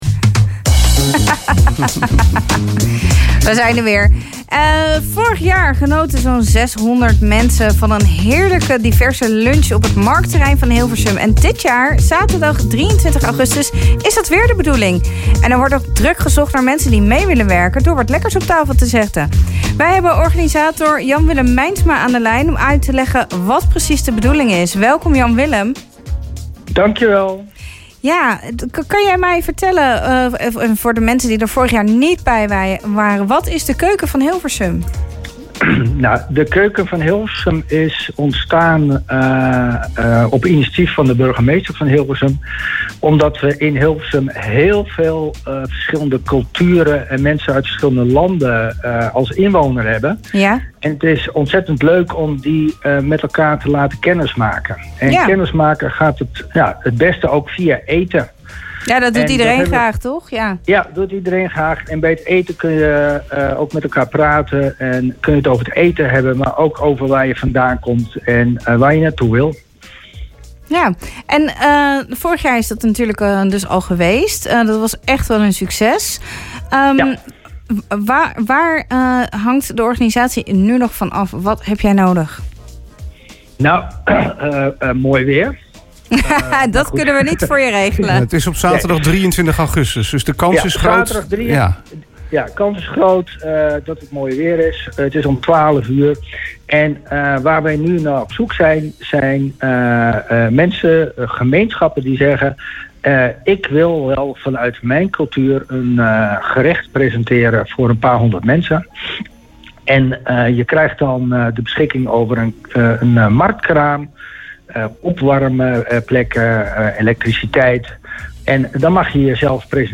is aan de lijn om uit te leggen wat de bedoeling is: